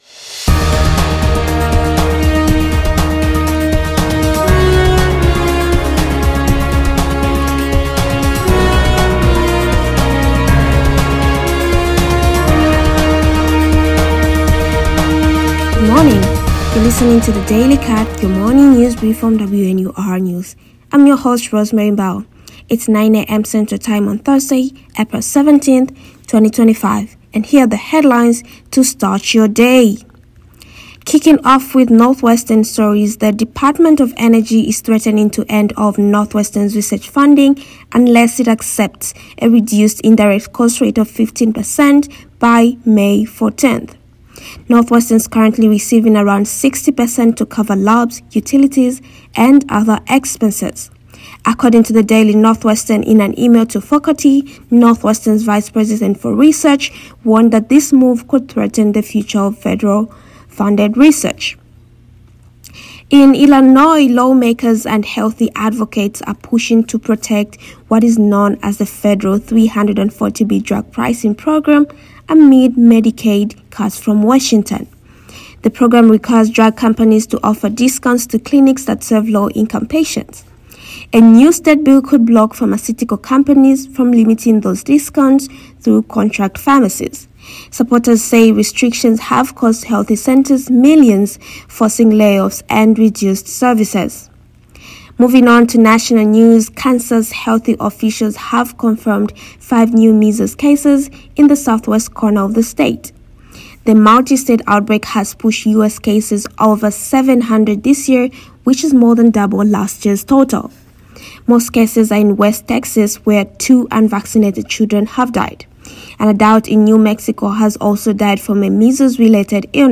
DATE: April 16, 2025 , Energy department threatens Northwestern, 340B pricing program,five Measles cases in Texas, Free federal filing termination. WNUR News broadcasts live at 6 pm CST on Mondays, Wednesdays, and Fridays on WNUR 89.3 FM.